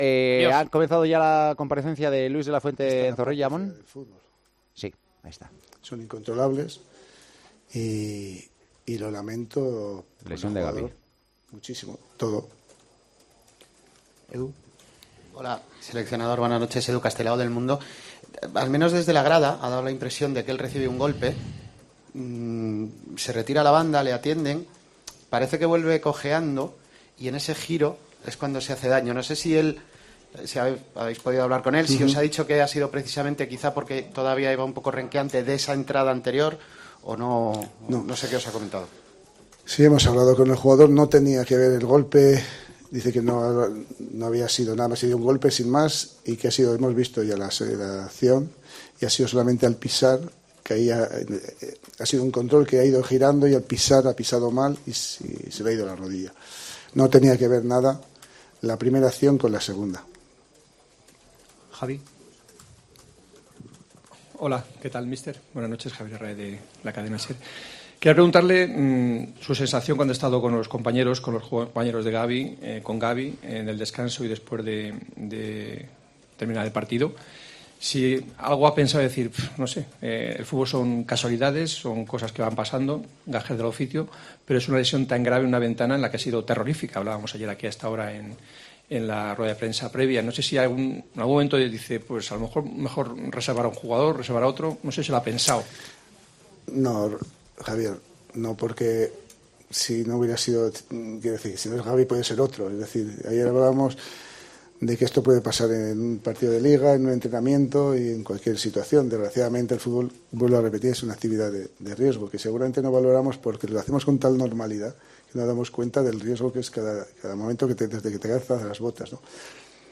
Rueda de prensa de Luis de la Fuente: "Al descanso, el vestuario era un velatorio"